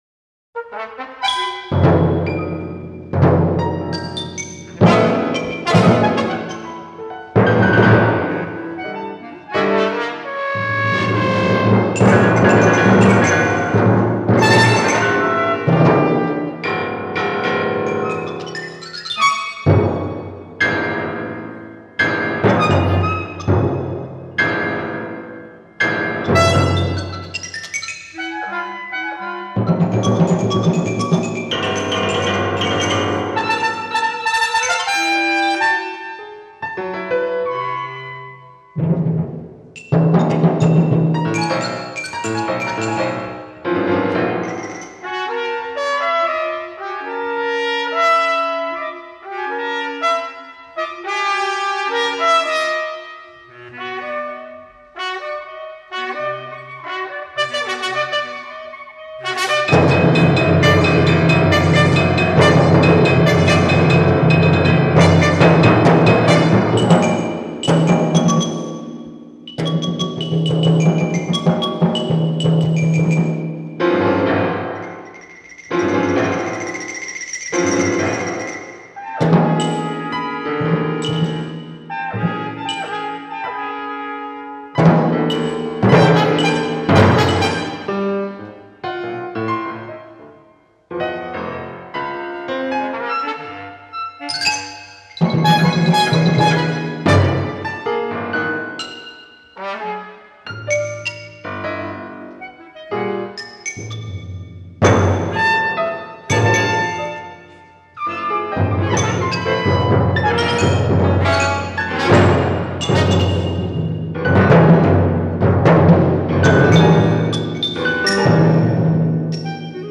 La instrumentación es clarinete o saxo soprano, clarinete bajo, trompeta, piano, xilófono y timbales.